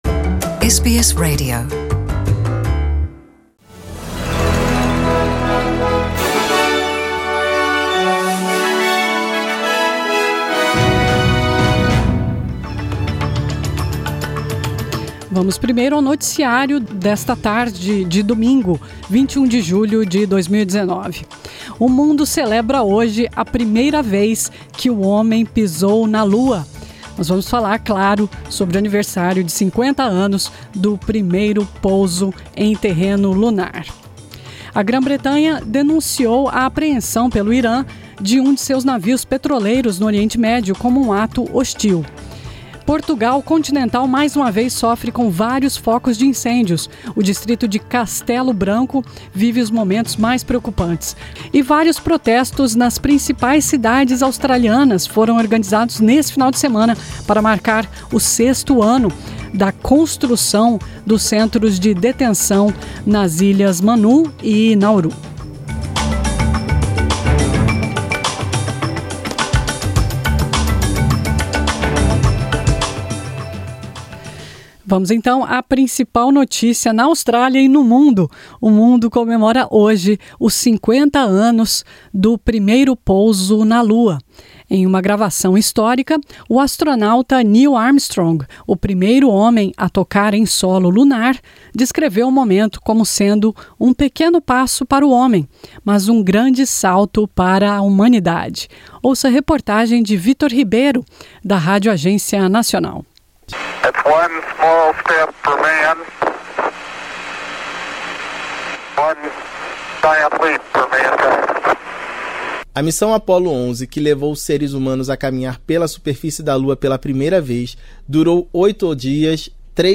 São as notícias da Austrália e do Mundo da rádio SBS para este domingo, 21 de julho de 2019.